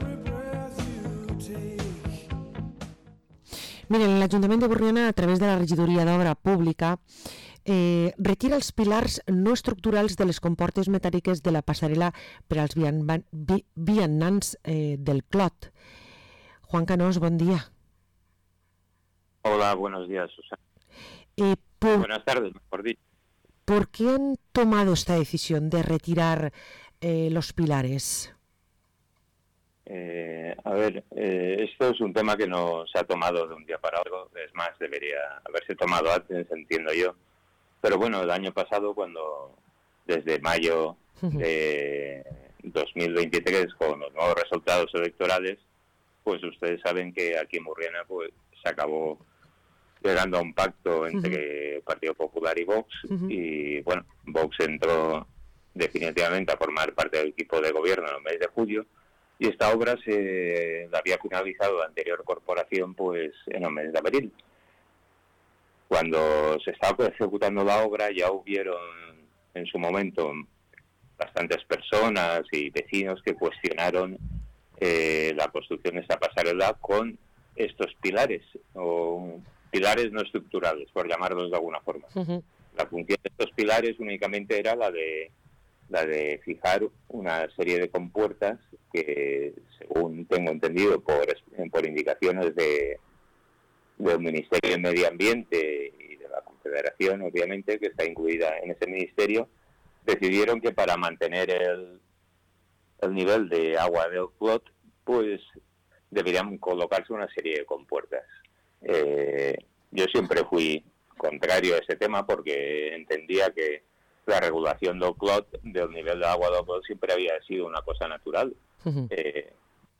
Parlem amb Juan Canós, regidor d´Urbanisme a l´Ajuntament de Burriana